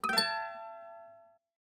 ui_empezar.wav